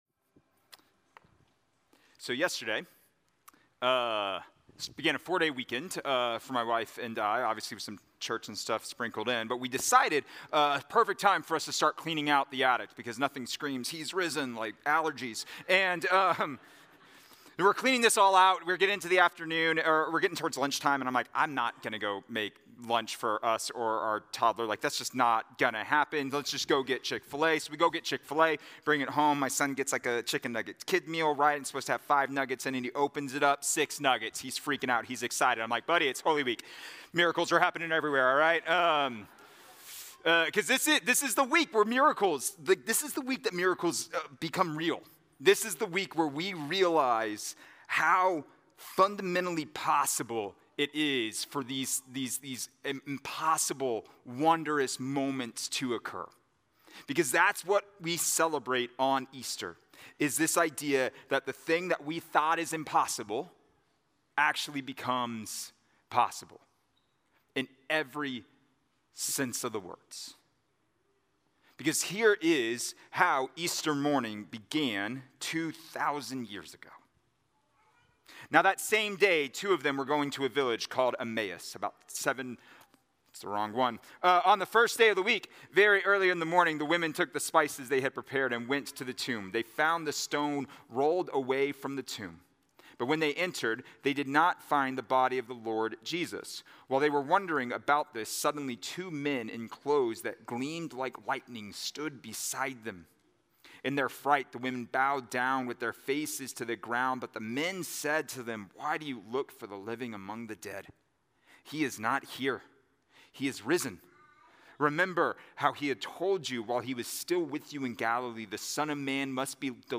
A message from the series "Easter 2025."